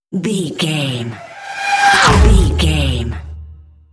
Sci fi vehicle whoosh fast
Sound Effects
futuristic
intense
whoosh